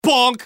Bonk Sound Effect Free Download
Bonk